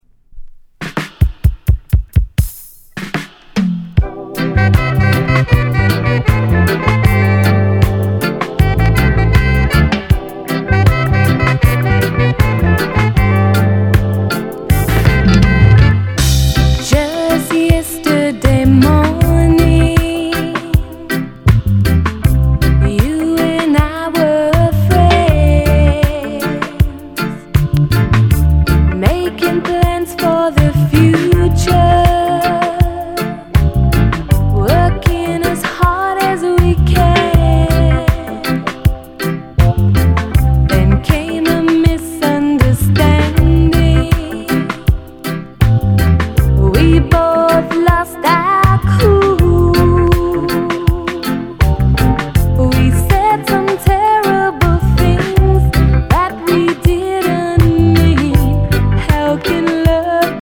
LOVERS ROCK